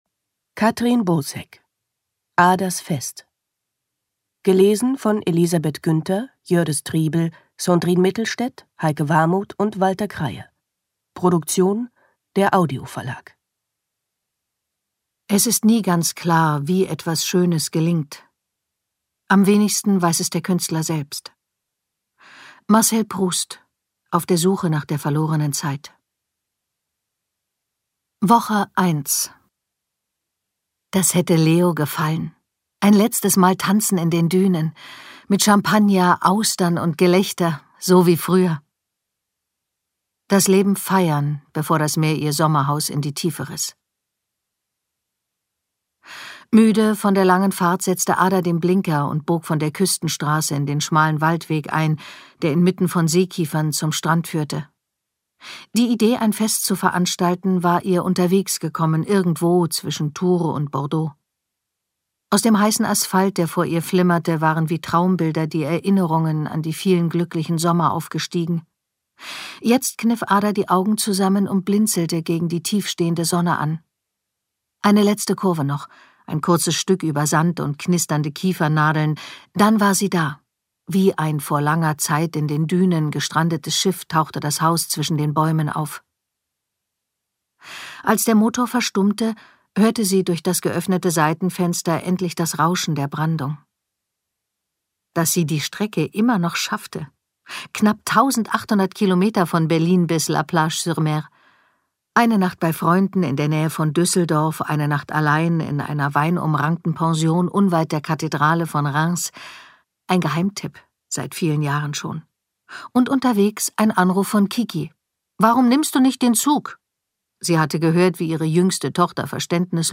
Als Hörbuchsprecherin begeistert sie mit ihren ausdrucksstarken Lesungen das Publikum.